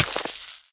freeze_hurt5.ogg